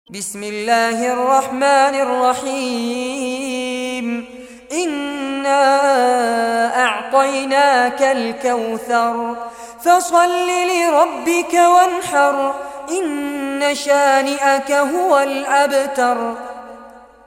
Surah Kausar Recitation by Sheikh Fares Abbad
Surah Kausar, listen or play online mp3 tilawat / recitation in Arabic in the beautiful voice of Sheikh Fares Abbad.